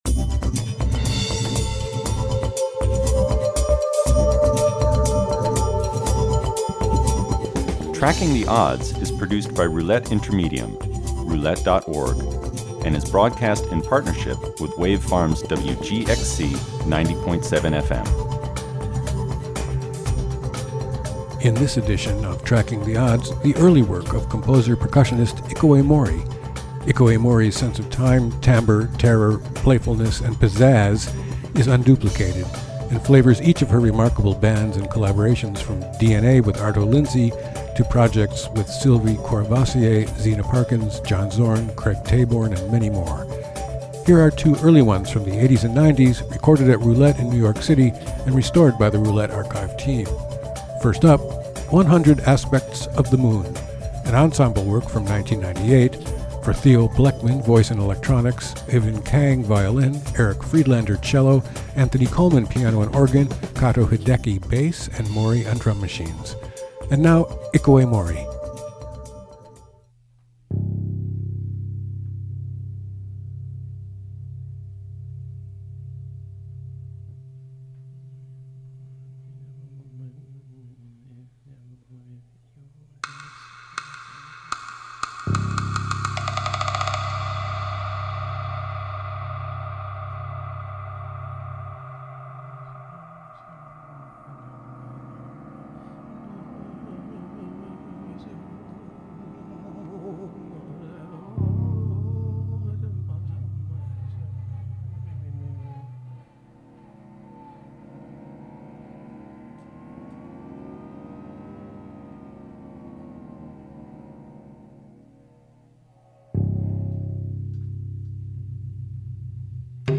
voice and electronics
violin
cello
piano and organ
drum machines
guitar
accordion